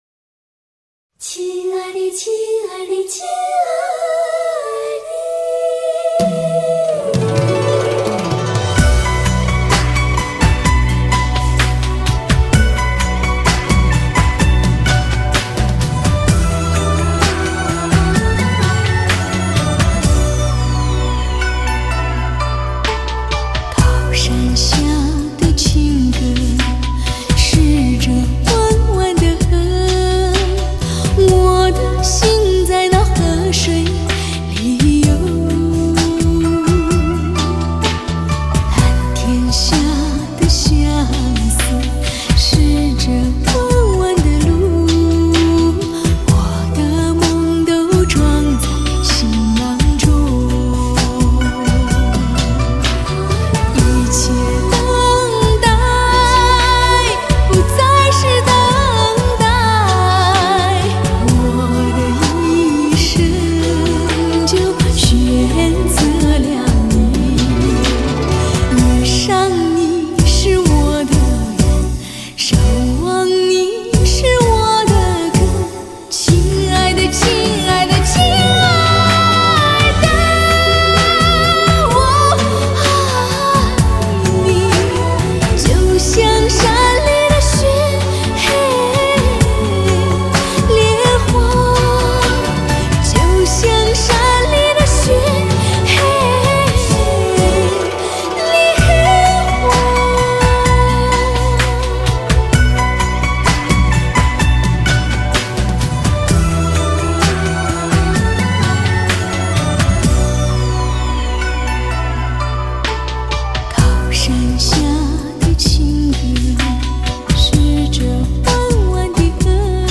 藏歌经典